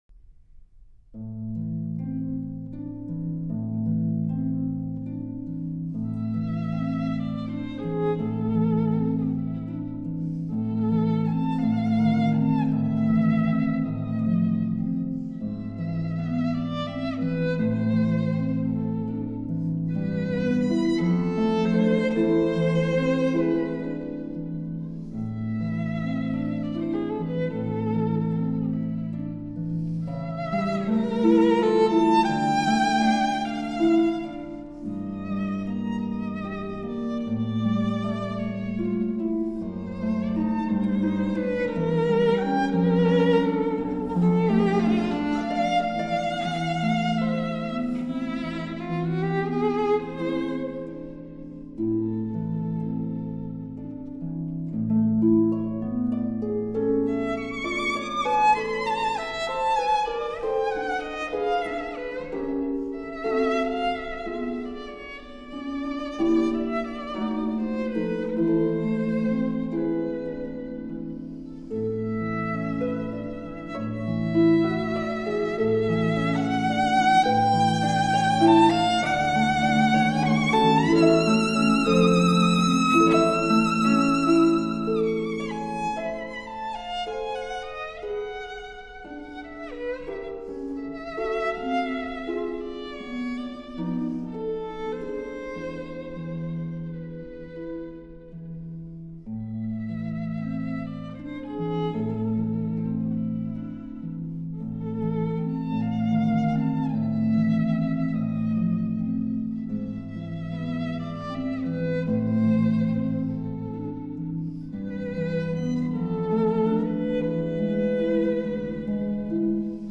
per violino ed arpa